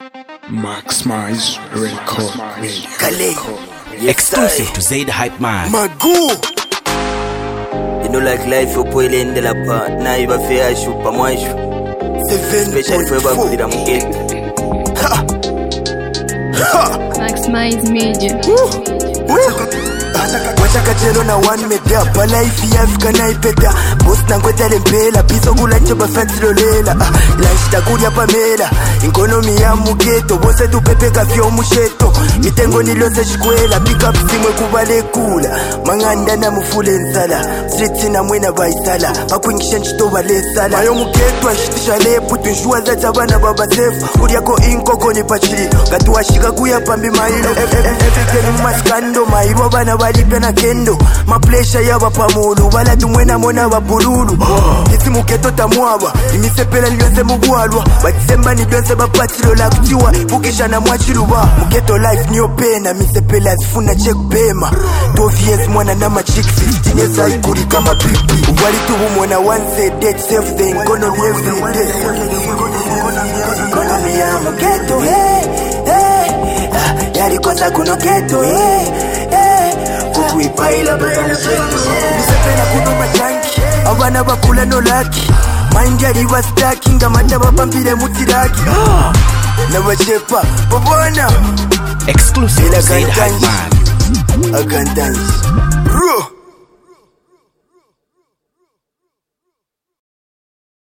HipHop
massive rap banger
struggle fused trap/HipHop tune